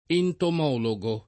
[ entom 0 lo g o ]